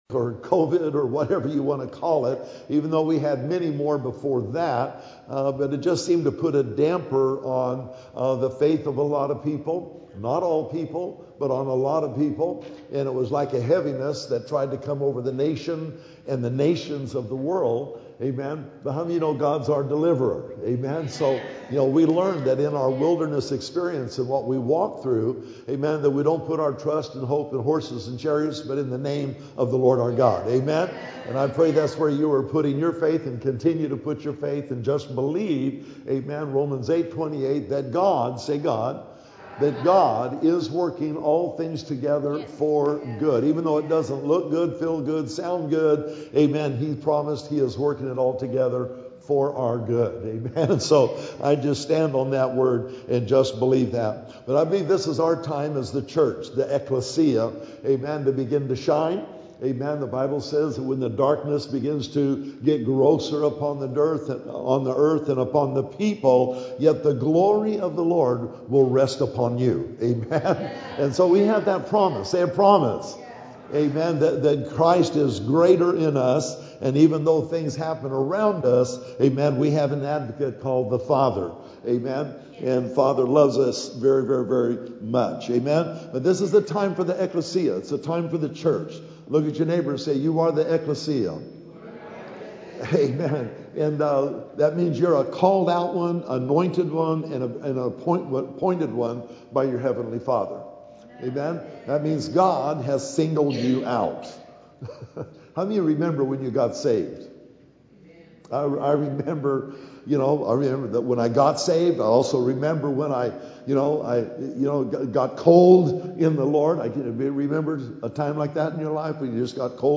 Sermons Archive - River of Life Community Church